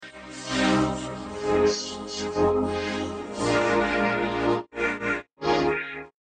Klasky Csupo 1993 Electronic Sounds